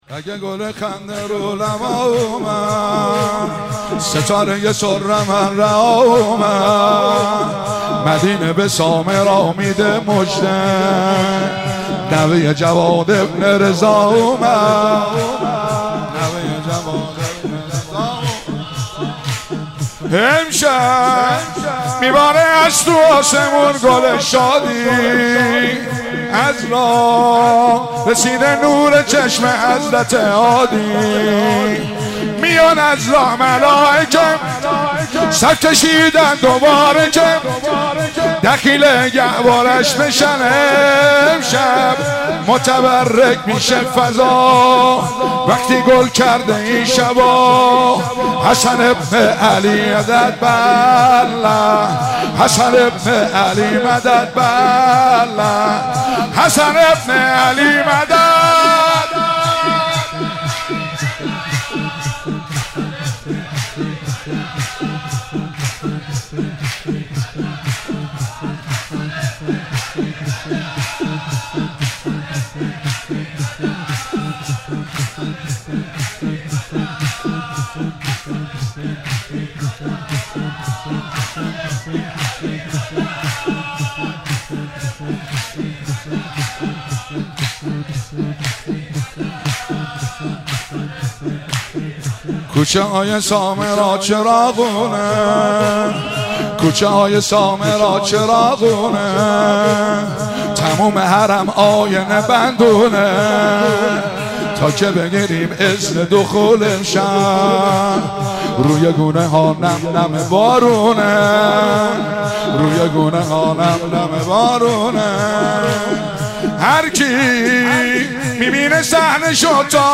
سرود- اگه گل خنده رو لبا اومد
جشن میلاد امام حسن عسکری (ع)- آبان 1401